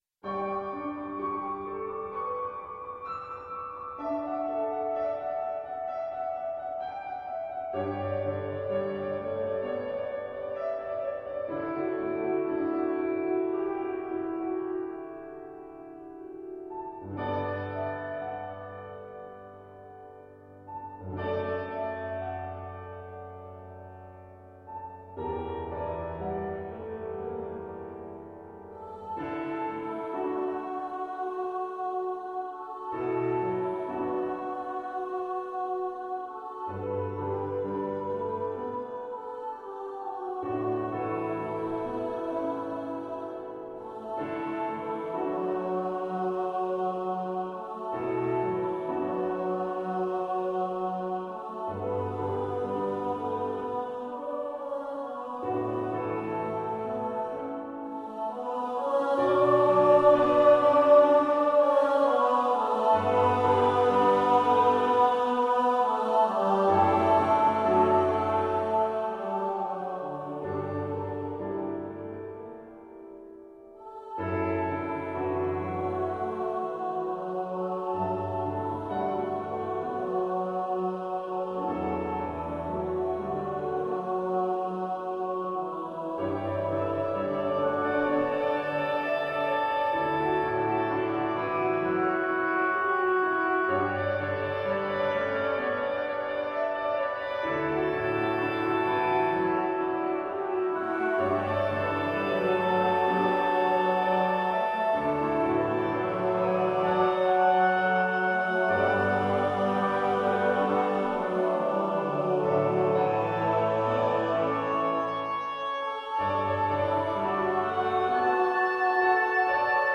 handbells and oboe or other C Instrument
SATB choral anthem